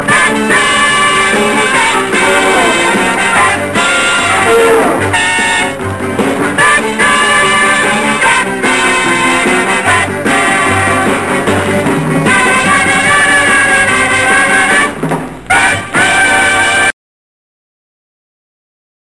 batman-punch_30740.mp3